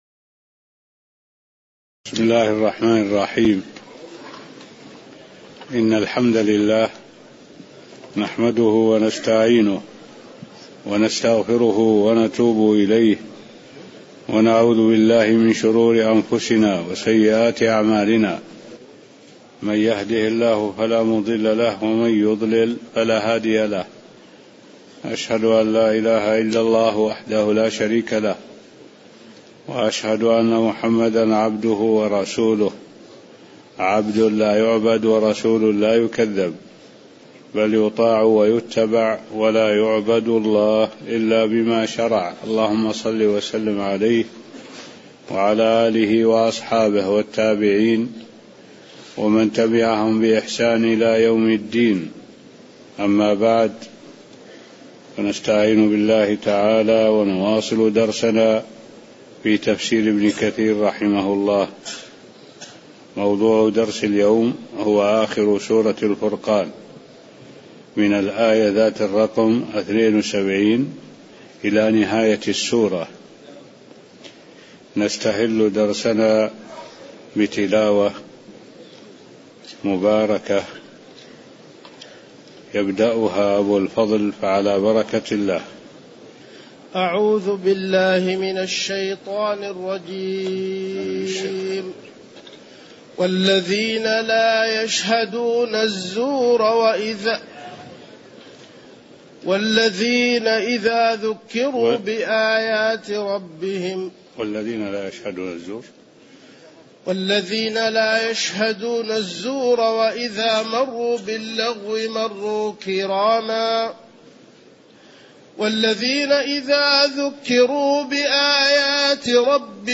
المكان: المسجد النبوي الشيخ: معالي الشيخ الدكتور صالح بن عبد الله العبود معالي الشيخ الدكتور صالح بن عبد الله العبود من آية رقم 72 إلى نهاية السورة (0819)